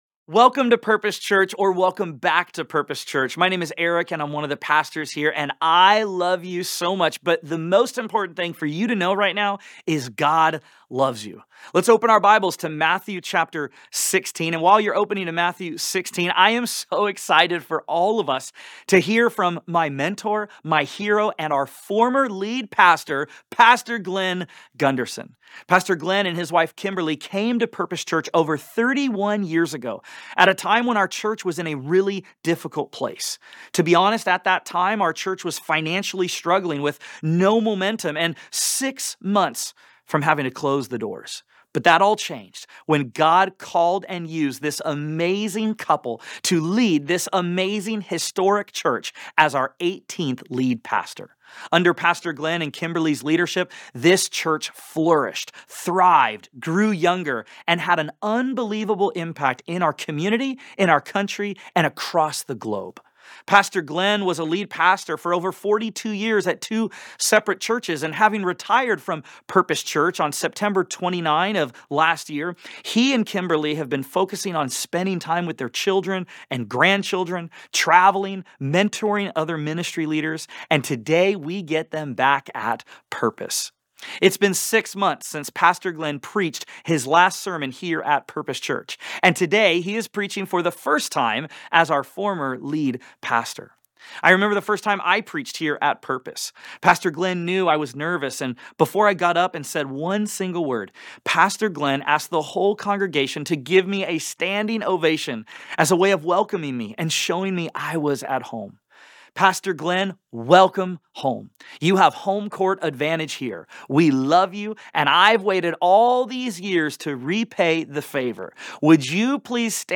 In this message, we explore Matthew 16 and the different kinds of faith—no faith, little faith, saving faith, and serving faith. From the Pharisees demanding a sign to Peter’s confession of Christ, we see how Jesus calls us to move beyond misunderstanding to full surrender.